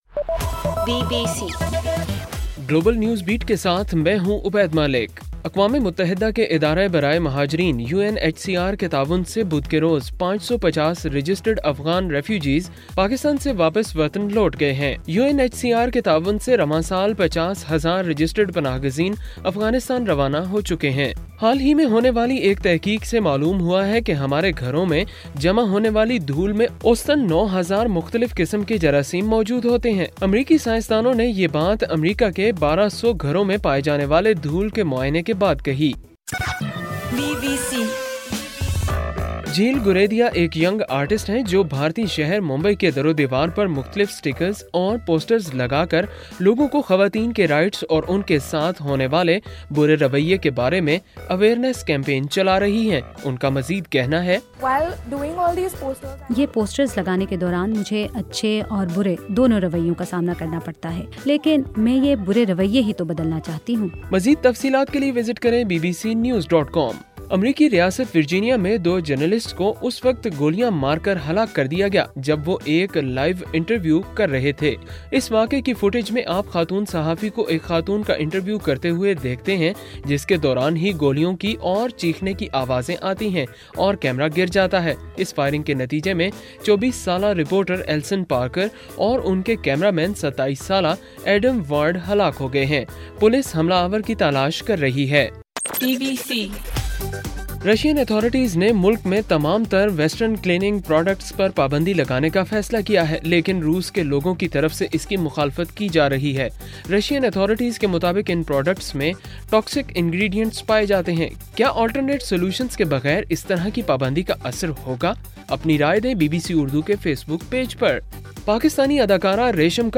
اگست 26: رات 10 بجے کا گلوبل نیوز بیٹ بُلیٹن